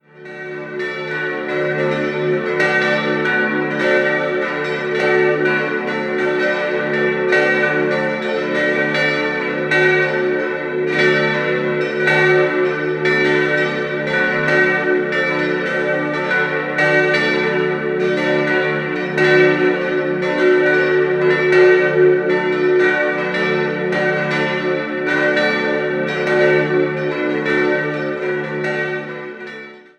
Die Weihe erfolgte im Jahr 1965. 5-stimmiges Geläute: d'-fis'-a'-h'-d'' Die Glocken wurden von Rudolf Perner in Passau gegossen.